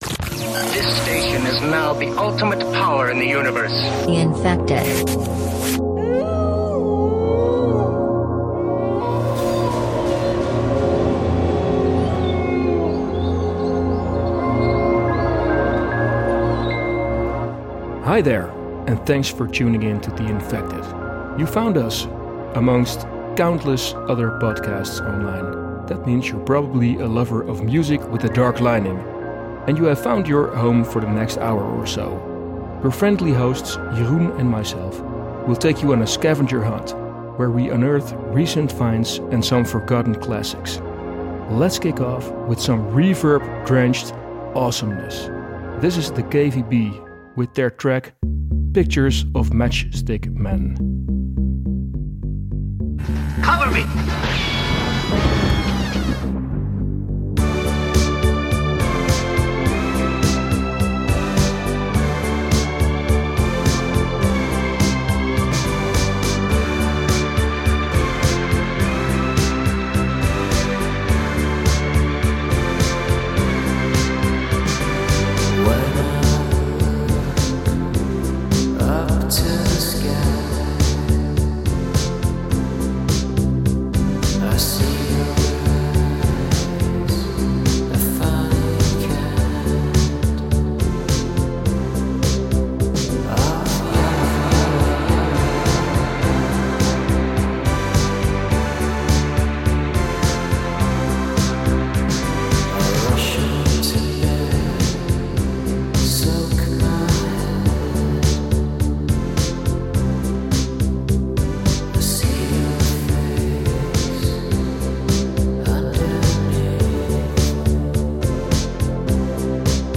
Your hosts select the finest Post Punk, Alternative, Industrial, New Wave, Goth and Darksynth tracks. We bring you the stories behind hot new releases and classic songs, as well as sometimes some Goth approved movie tips.